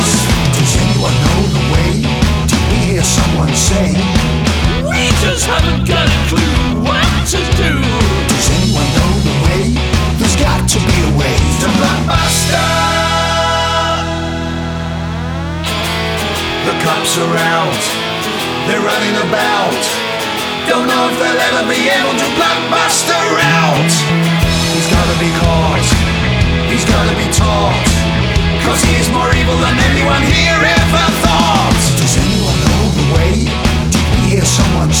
2020-12-18 Жанр: Рок Длительность